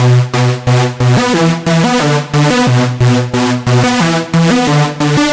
描述：派对歌曲的恍惚循环，180bpm
Tag: 180 bpm Trance Loops Synth Loops 918.79 KB wav Key : C